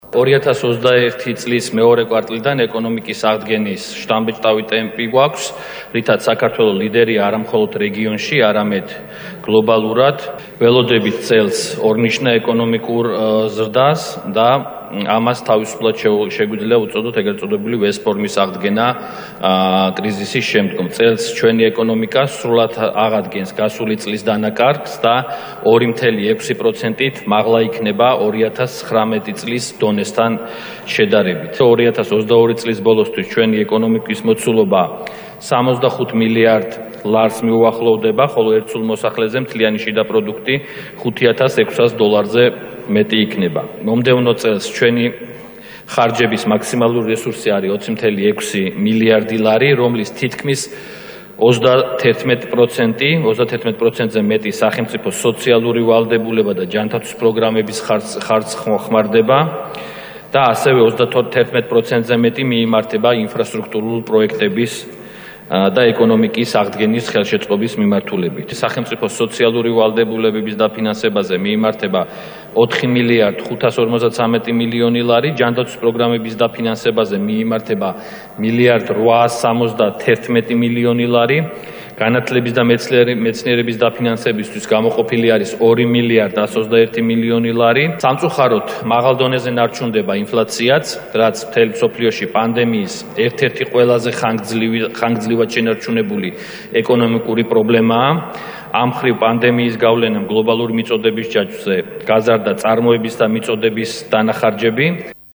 ფინანსთა მინისტრის ლაშა ხუციშვილის ხმა